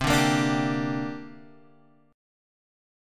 Bb6/C chord